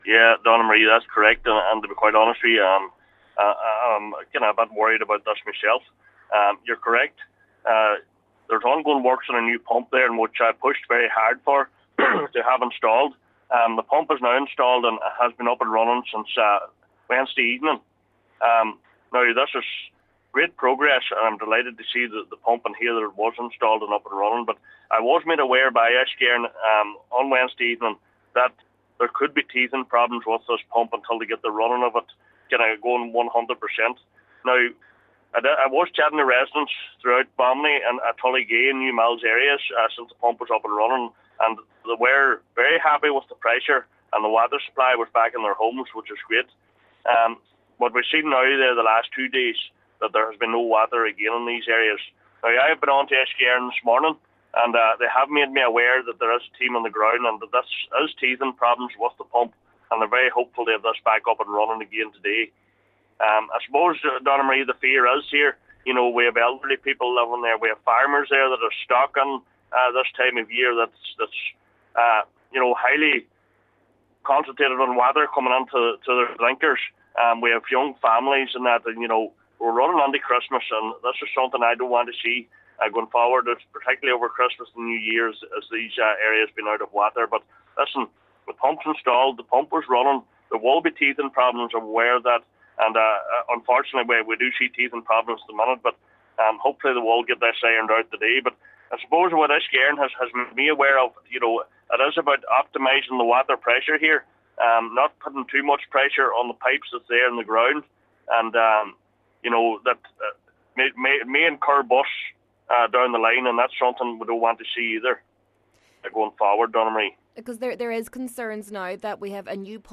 Meanwhile, Cllr Donal Mandy Kelly says he understands the fears of those living in the area.